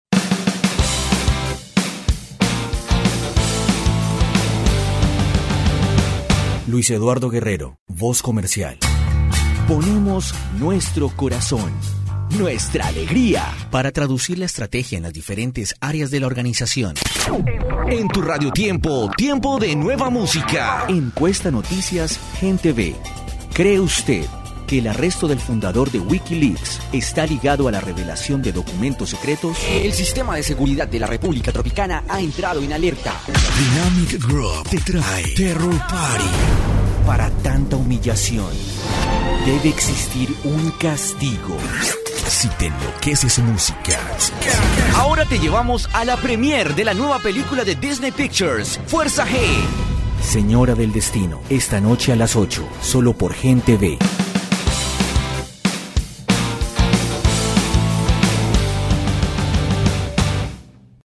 Acento español neutro. Los diferentes tonos que puedo lograr son ideales para comerciales, documentales y promos.
Sprechprobe: Werbung (Muttersprache):
Neutral Spanish accent. The different tones I can achieve are ideal for commercials, documentaries and promos.